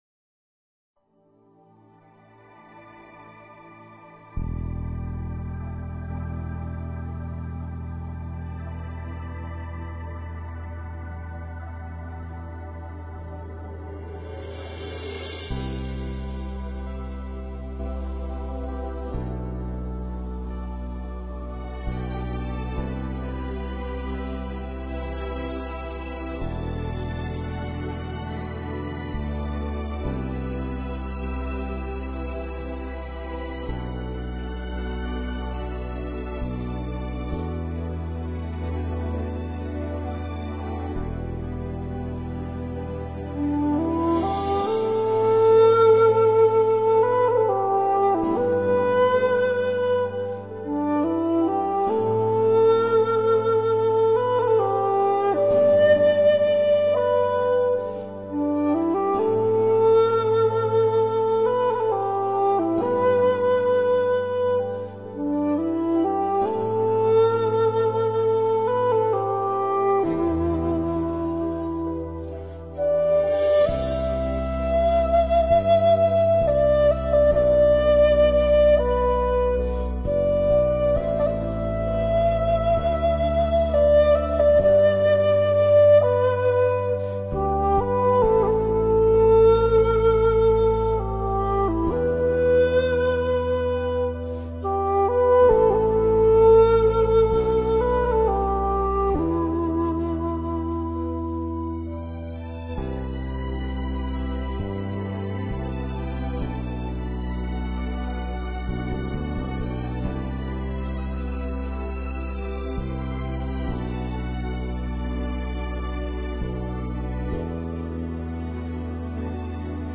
一段经典的纯音乐